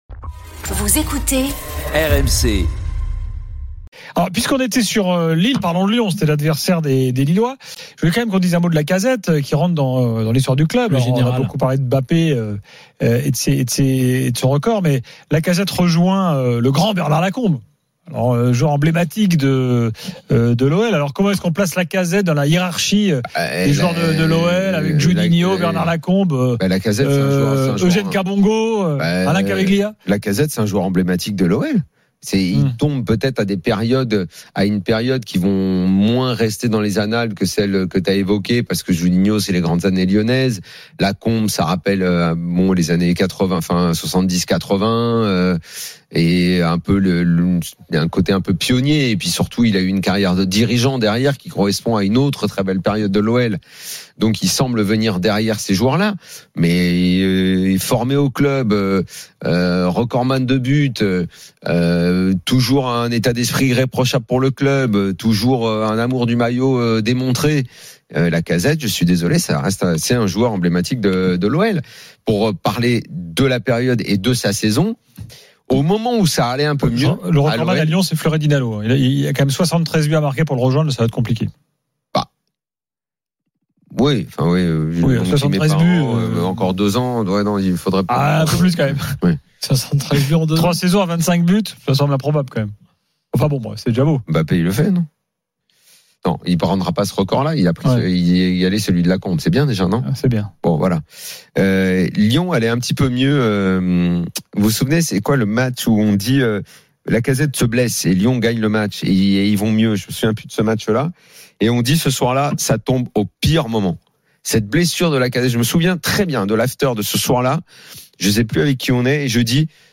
Les rencontres se prolongent tous les soirs avec Gilbert Brisbois et Nicolas Jamain avec les réactions des joueurs et entraîneurs, les conférences de presse d’après-match et les débats animés entre supporters, experts de l’After et auditeurs. RMC est une radio généraliste, essentiellement axée sur l'actualité et sur l'interactivité avec les auditeurs, dans un format 100% parlé, inédit en France.